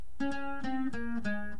para bajosexto!!!!!